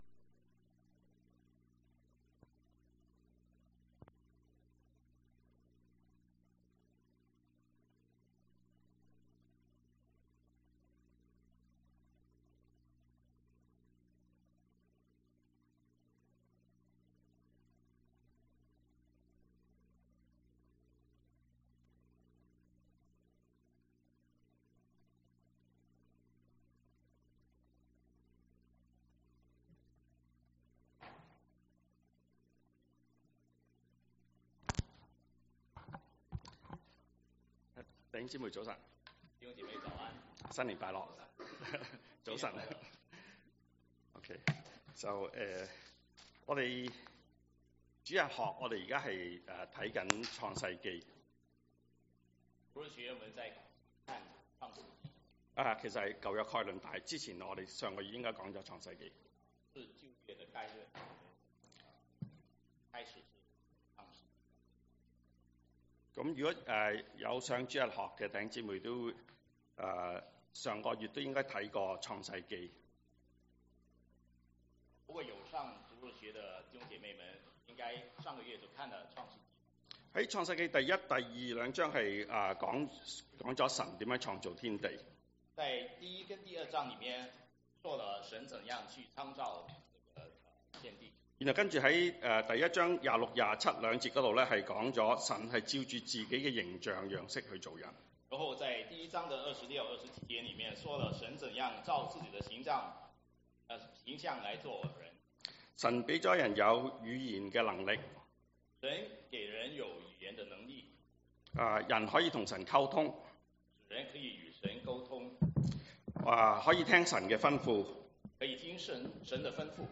中文講道